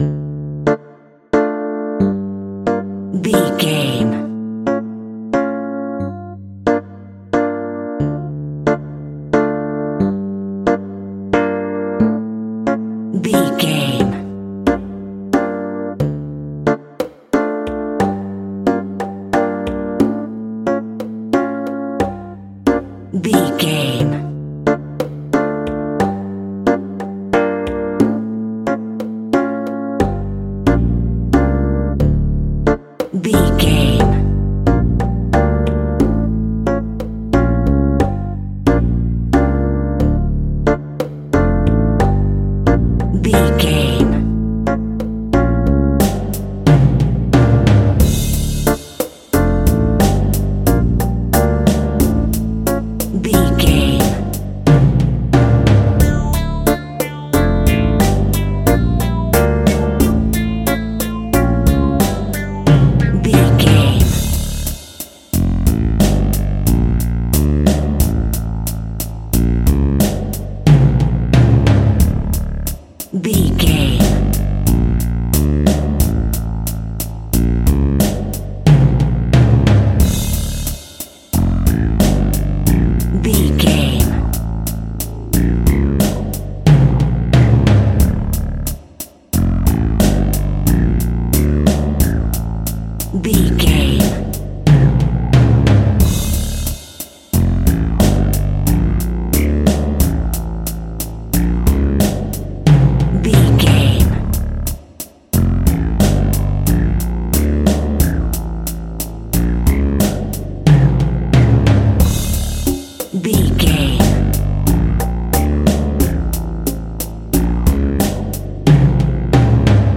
Aeolian/Minor
D
scary
ominous
dark
suspense
eerie
synthesiser
percussion
conga
bass guitar
drums
horror